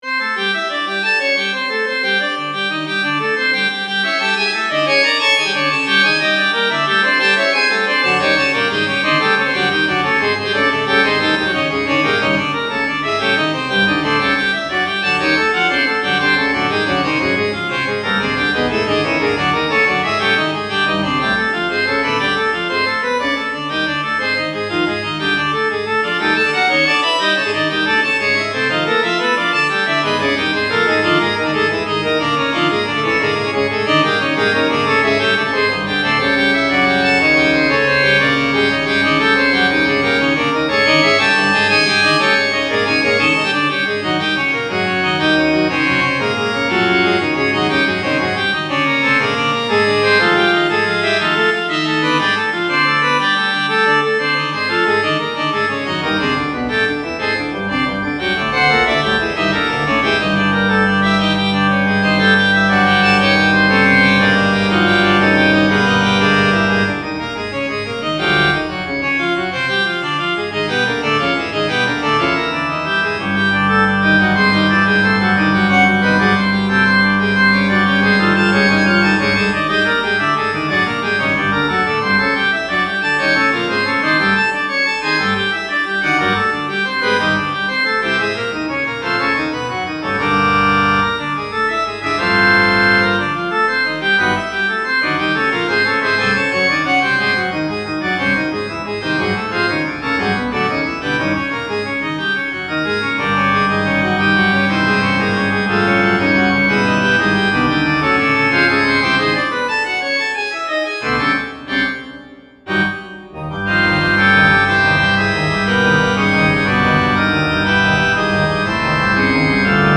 midi keyboard computer, player piano [10']
MIDI organ